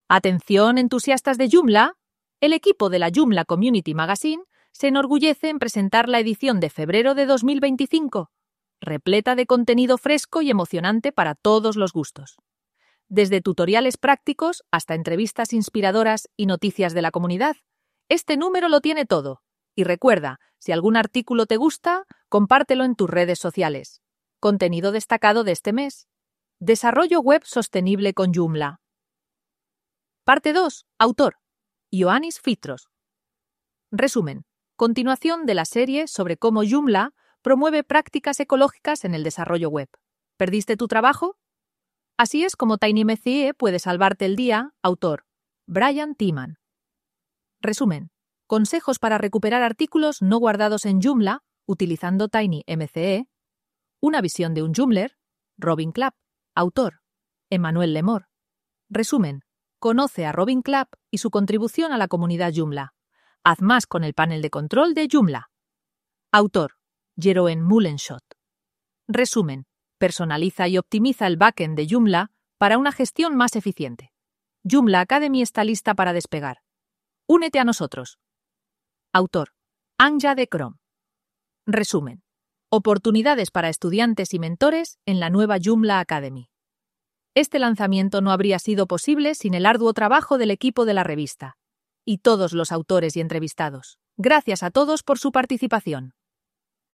Podcast sobre Joomla en español con JoomlIA Robers, una IA